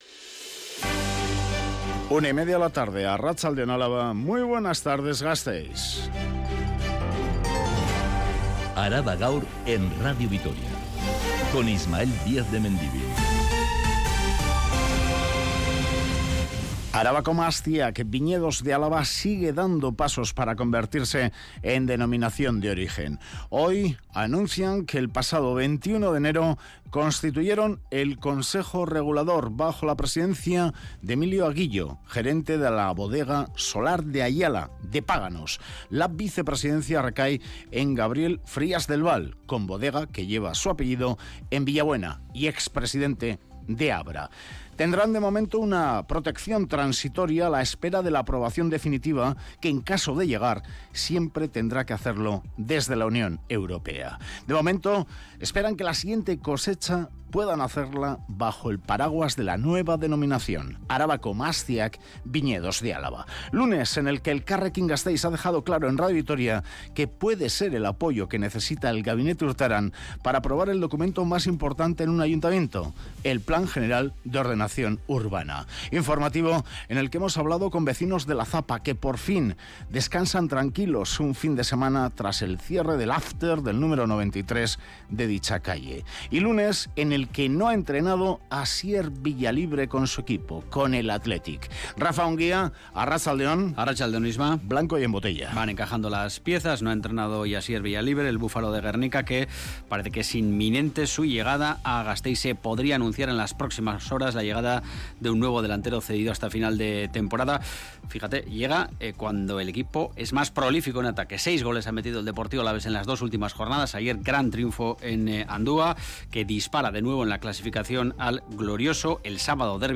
Radio Vitoria ARABA_GAUR_13H Araba Gaur (Mediodía) (30/01/2023) Publicado: 30/01/2023 14:59 (UTC+1) Última actualización: 30/01/2023 14:59 (UTC+1) Toda la información de Álava y del mundo. Este informativo que dedica especial atención a los temas más candentes de la actualidad en el territorio de Álava, detalla todos los acontecimientos que han sido noticia a lo largo de la mañana.